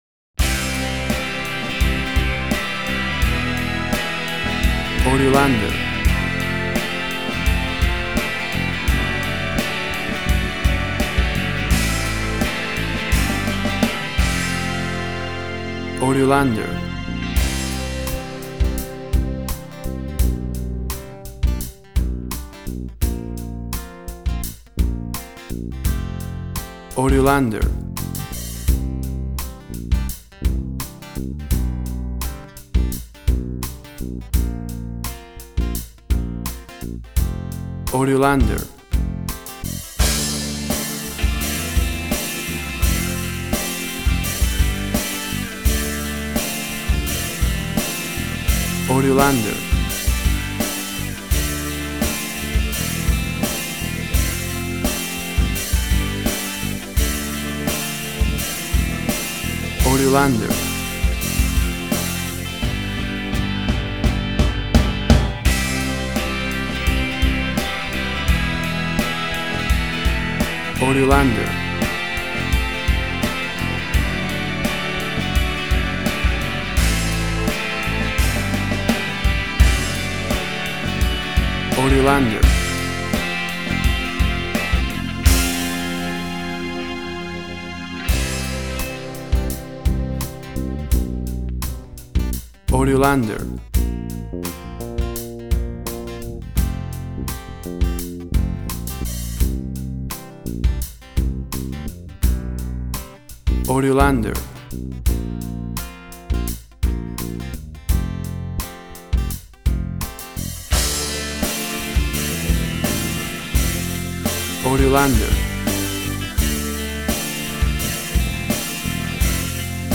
A typical mid 90�s Brit Rock track.
lots of pop guitars, soaring chorus line
Tempo (BPM): 84